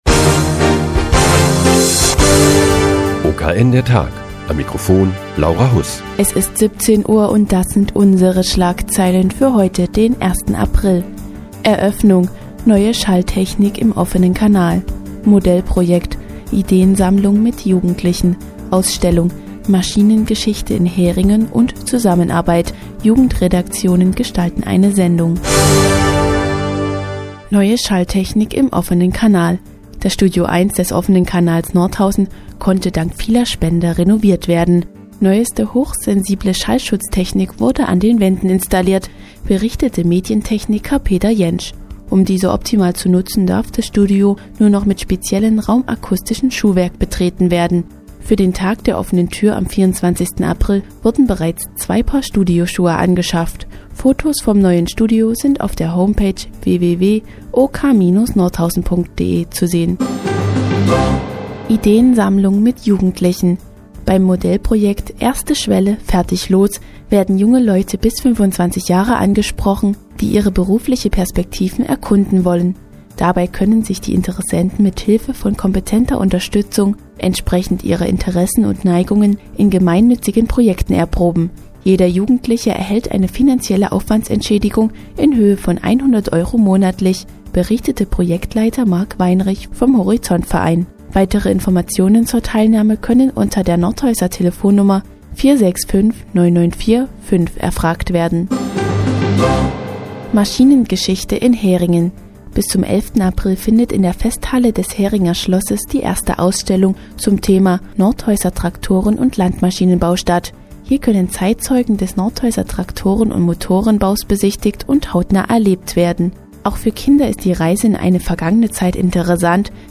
Die tägliche Nachrichtensendung des OKN ist nun auch in der nnz zu hören. Heute geht es um das neue, raumakustische Schuhwerk, das ab sofort im neuen OKN-Studio getragen werden muss.